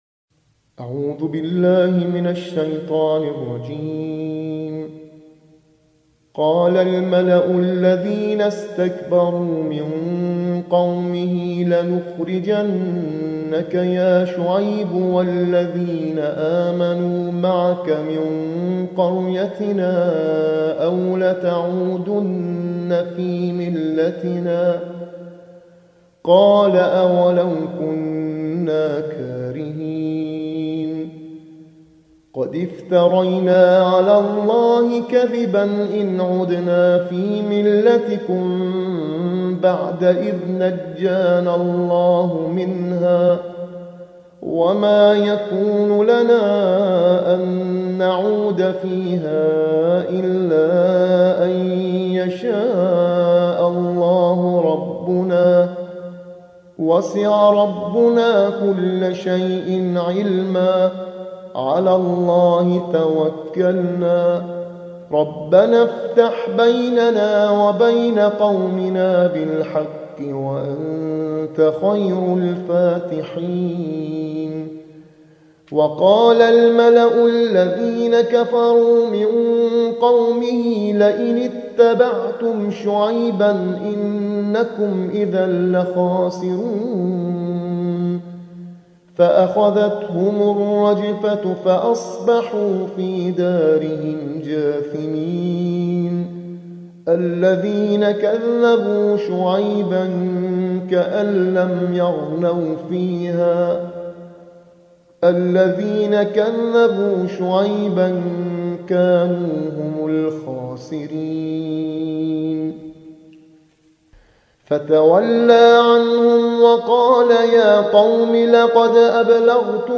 ترتیل‌خوانی